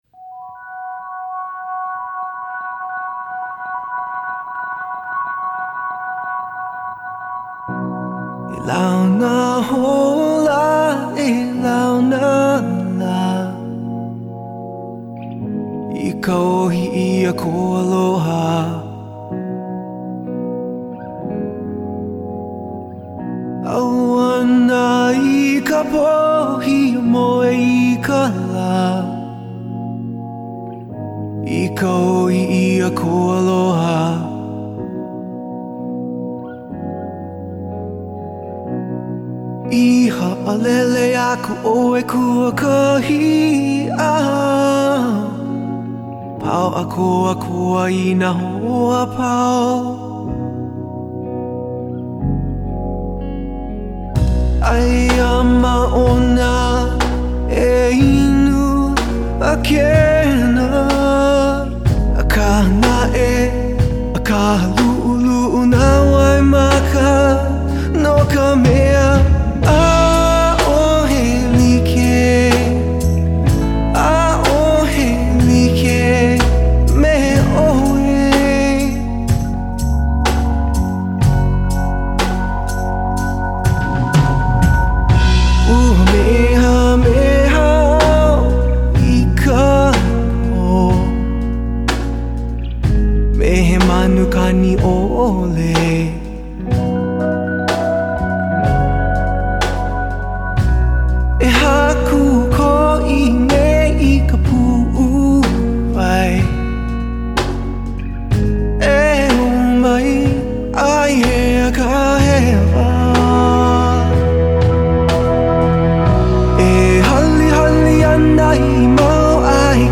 some guy singing Hawaiian